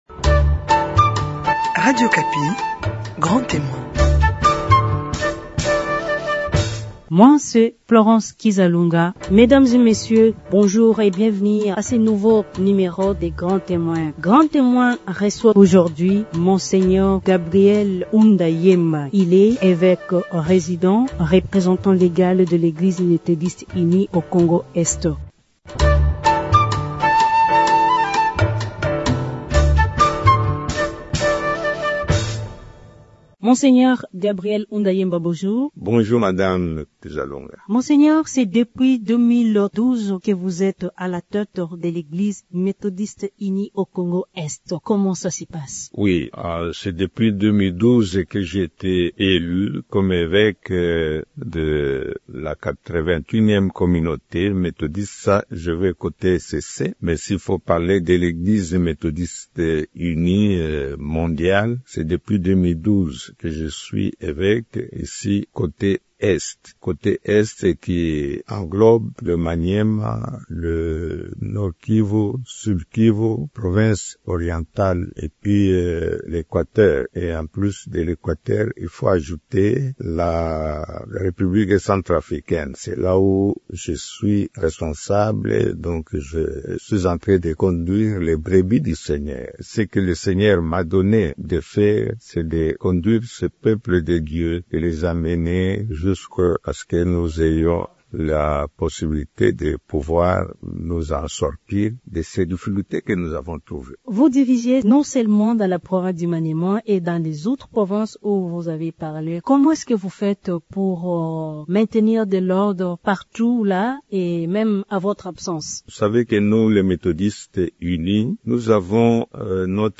Grand Témoin reçoit Monseigneur Gabriel Unda Yemba, évêque résident, représentant légal de l’Église Méthodiste Unie au Congo Est.